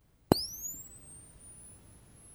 flash.wav